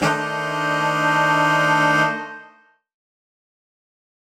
UC_HornSwellAlt_Bminb5.wav